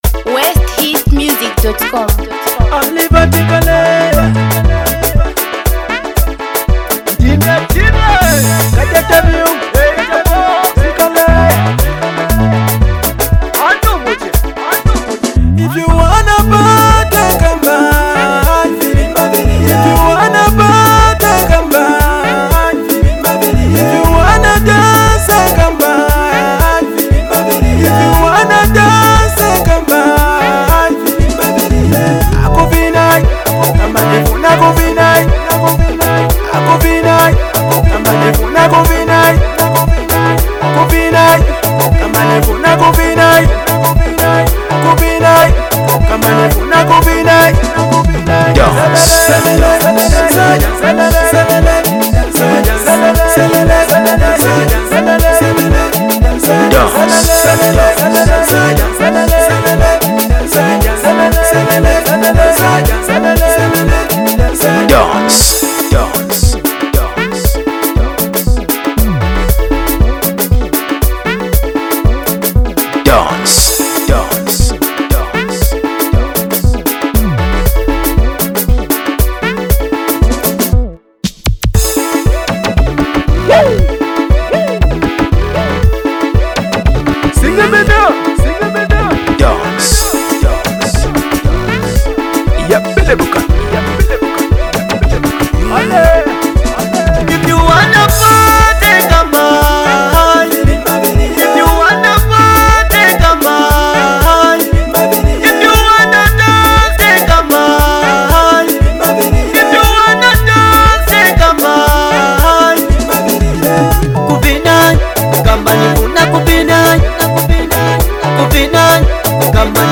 Karindula MusicZambia Music